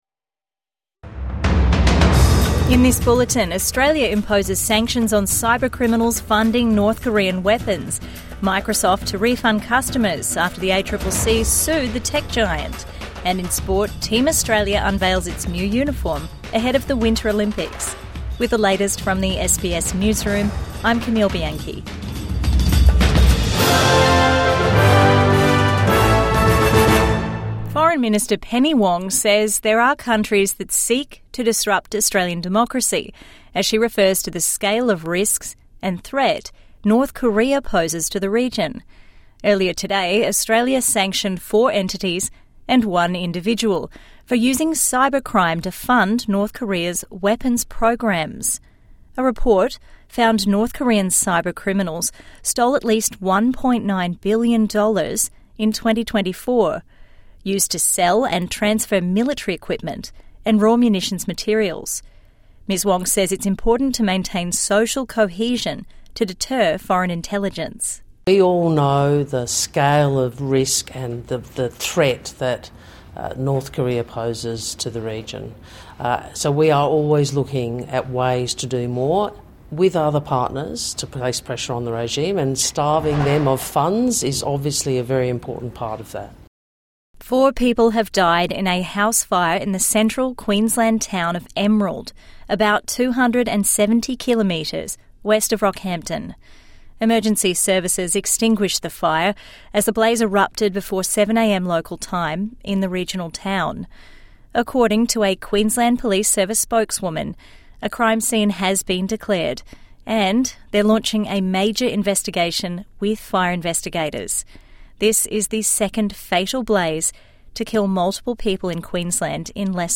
Midday News Bulletin